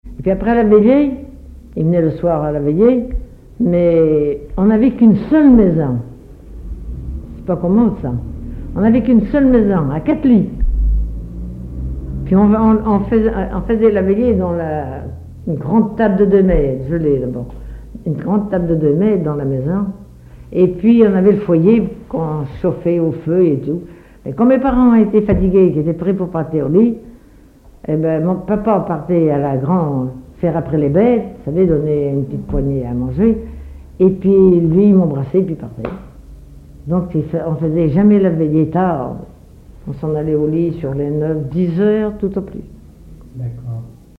Témoignages et chansons traditionnelles
Catégorie Témoignage